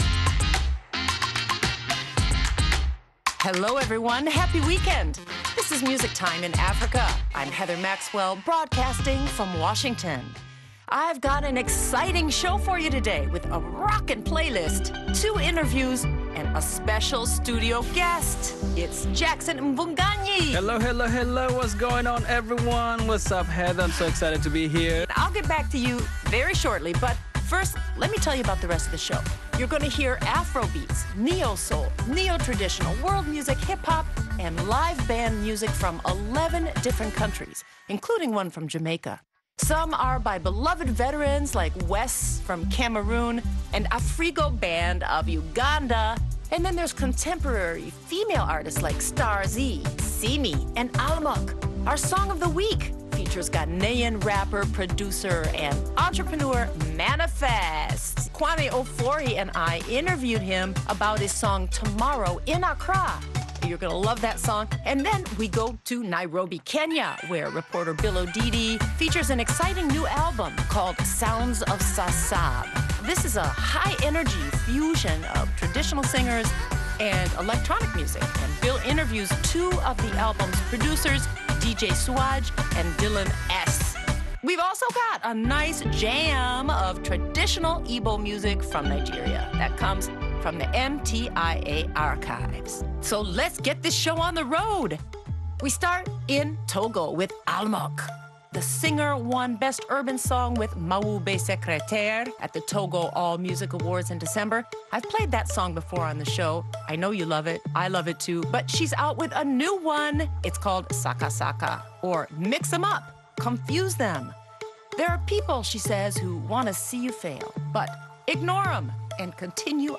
We also get a nice vintage jam of traditional Ibo music from Nigeria that comes from the MTIA Archives.